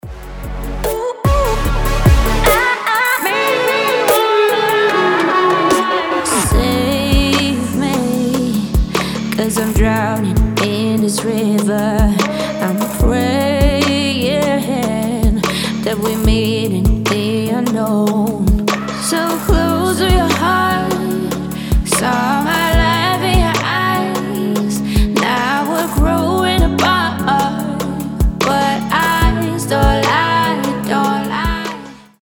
• Качество: 320, Stereo
мелодичные
красивый женский голос
future bass